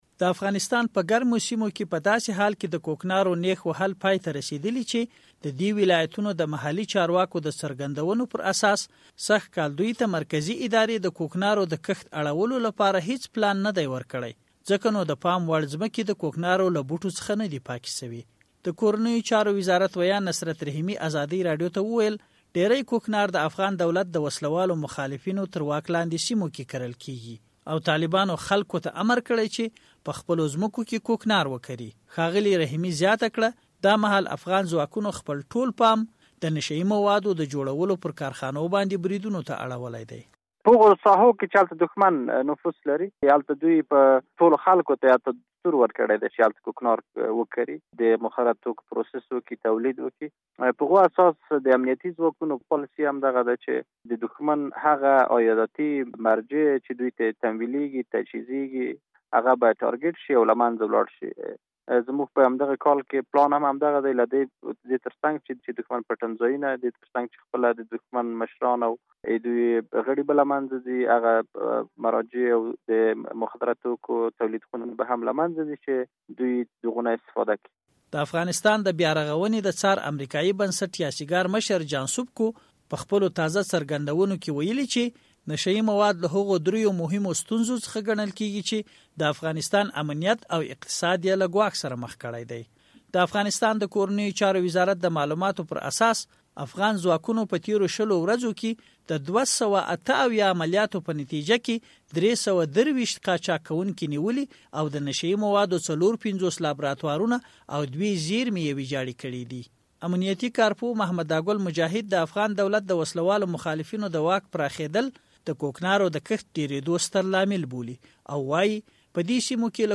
د کوکنارو راپور